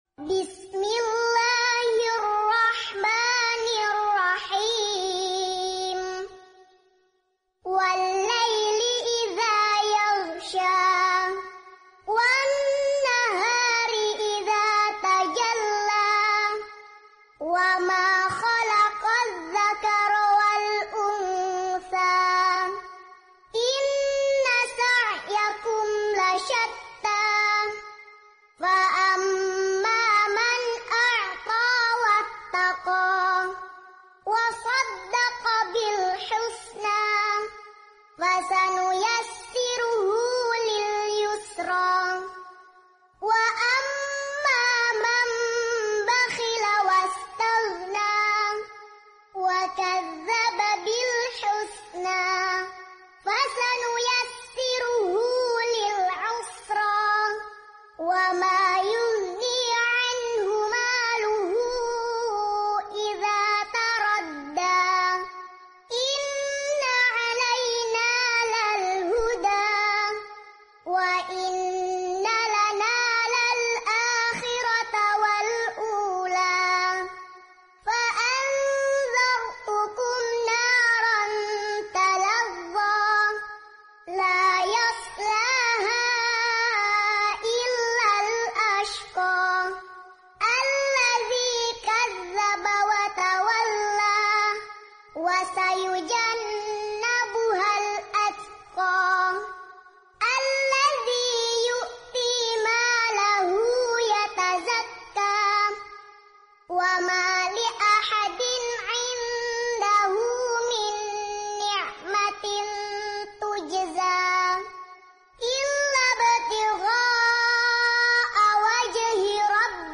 Ngaji Metode Ummi Murottal Anak, Juz Amma.